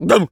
turkey_ostrich_hurt_yelp_04.wav